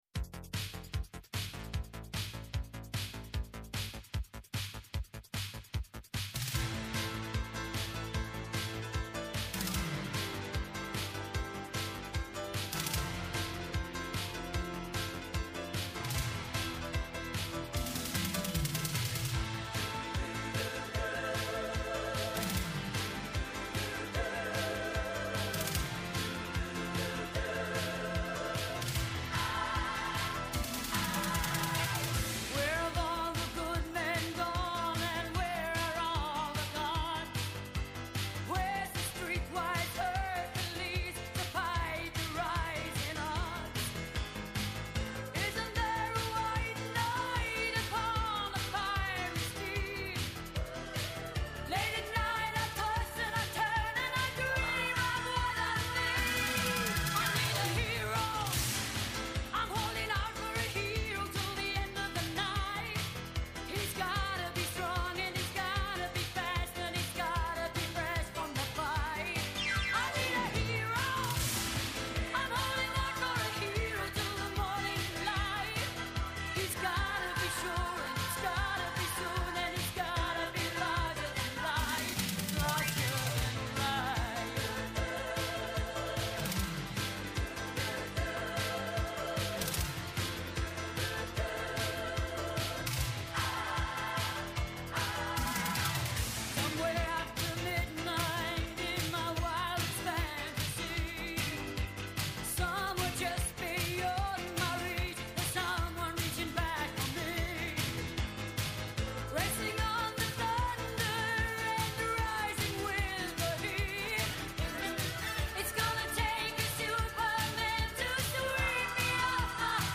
-Ο Δημήτρης Μαρκόπουλος, βουλευτής Νέας Δημοκρατίας
δήμαρχος Ζακύνθου Συμφωνούν, διαφωνούν, φωτίζουν και αποκρυπτογραφούν τα γεγονότα με πολύ κέφι, πολλή και καλή μουσική και πολλές εκπλήξεις. Με ζωντανά ρεπορτάζ από όλη την Ελλάδα, με συνεντεύξεις με τους πρωταγωνιστές της επικαιρότητας, με ειδήσεις από το παρασκήνιο, πιάνουν τιμόνι στην πρώτη γραμμή της επικαιρότητας.